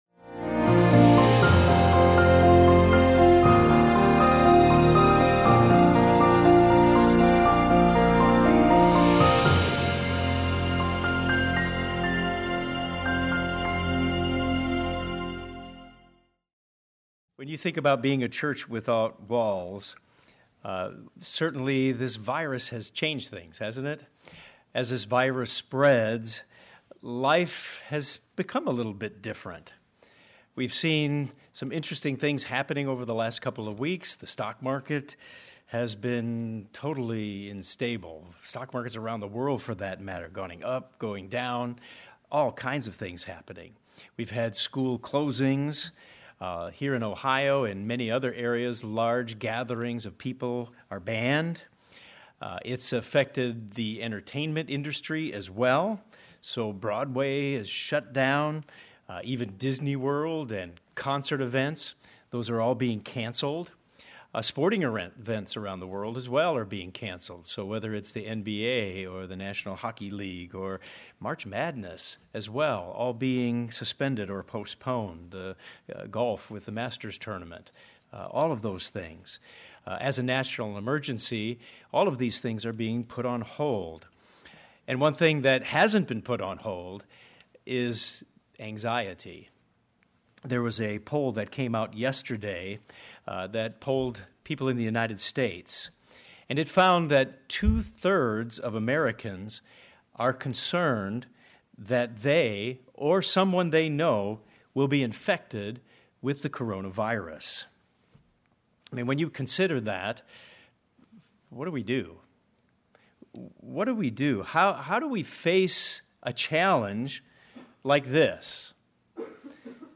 This sermon gives a biblical perspective on the dilemmas that life will bring and how we can face them with a positive spiritual approach.